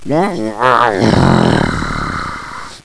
mummy_die1.wav